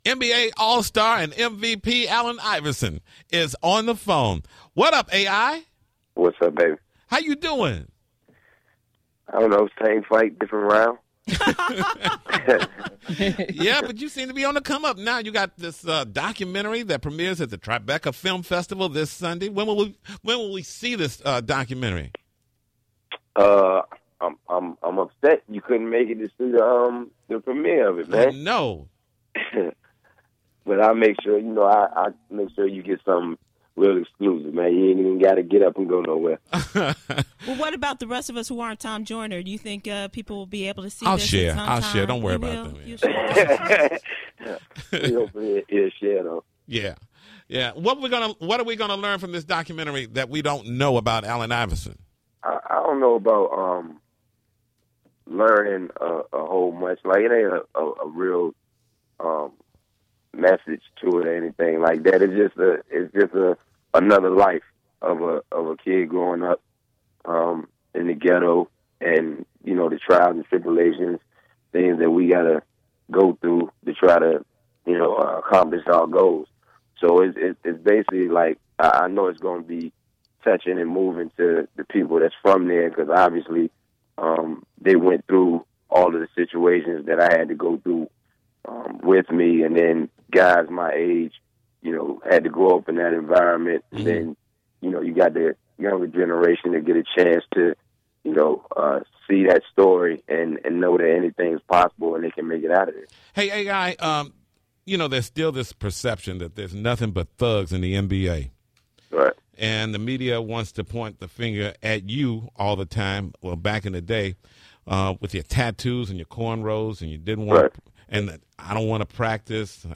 Read the entire interview below: